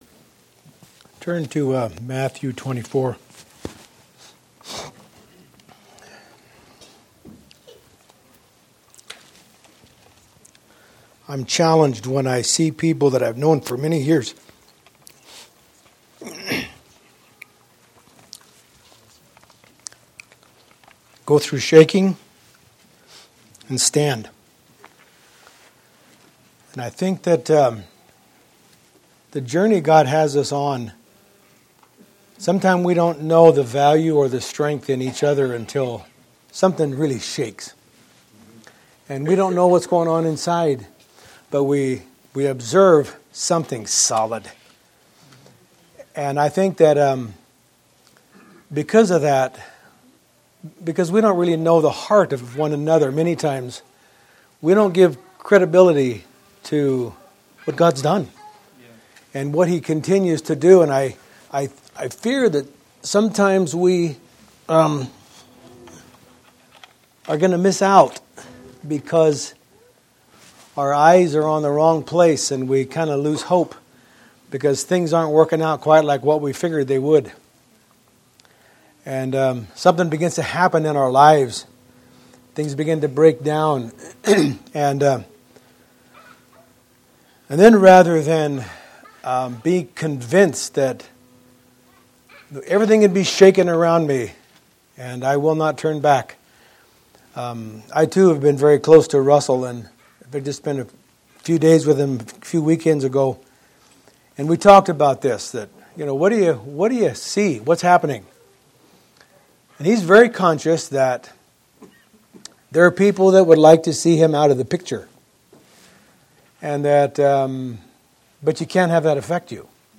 Posted in 2015 Shepherds Christian Centre Convention